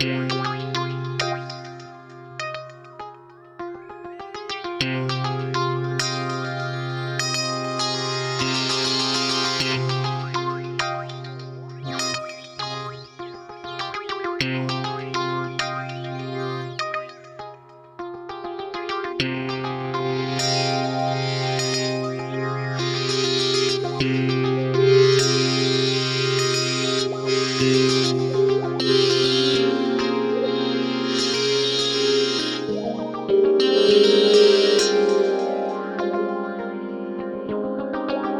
KarmicGuitar5_100_C.wav